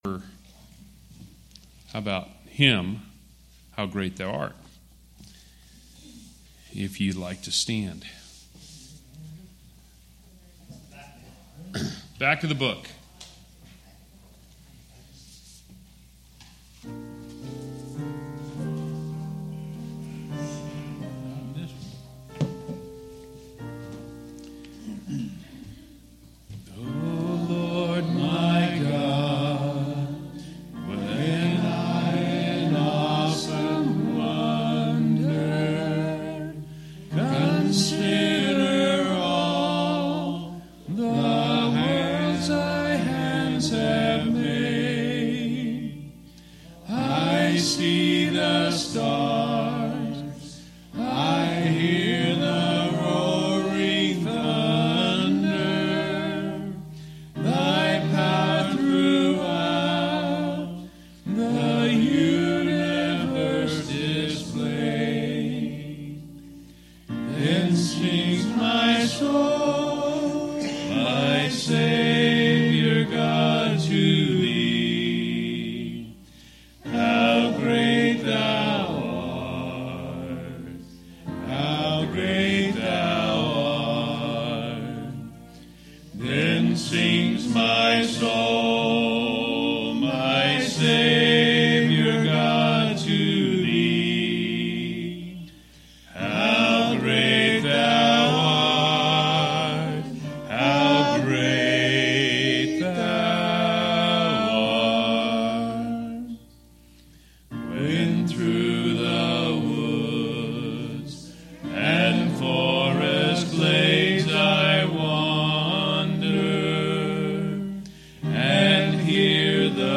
6/4/2016 Location: Idaho Reunion Event